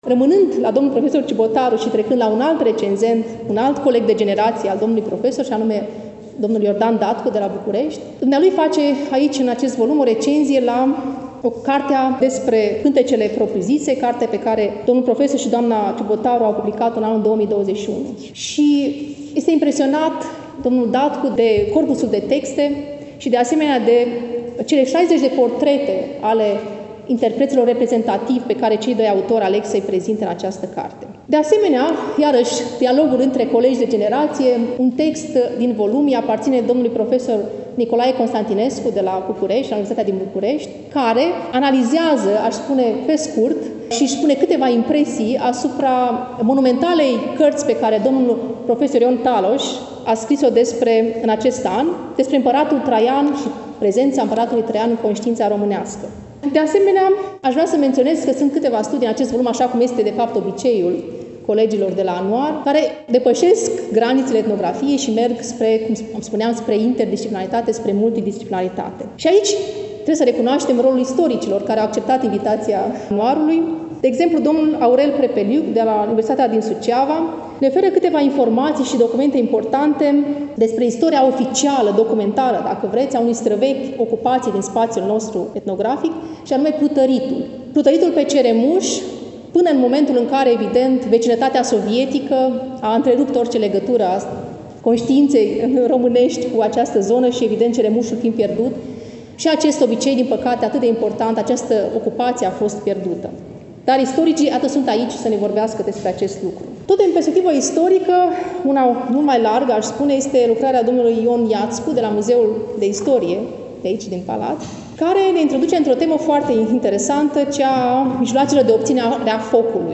Stimați prieteni, vă reamintim că relatăm de la prezentarea „Anuarului Muzeului Etnografic al Moldovei”, Nr. XXII, lansat, nu demult, la Iași, în Sala „Petru Caraman” din incinta Muzeului Etnografic al Moldovei, Palatul Culturii.